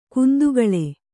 ♪ kundugaḷe